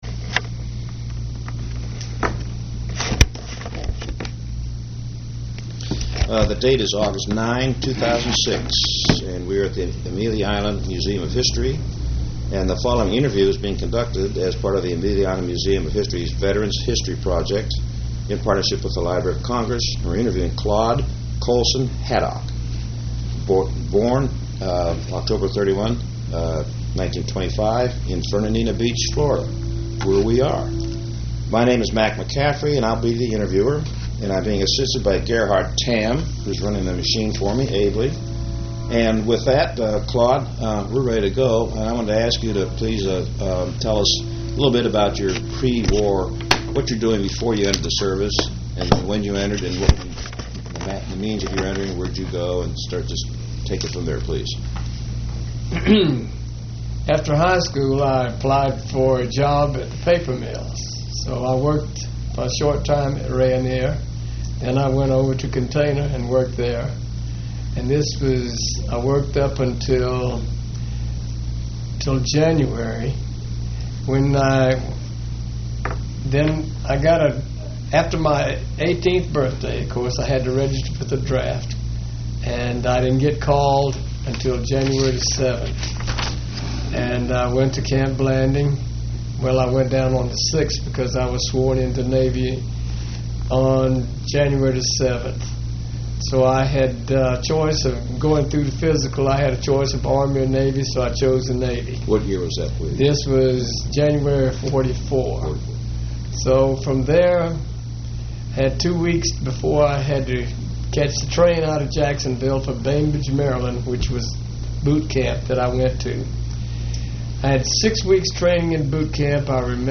Click Here to play the Oral History Recording.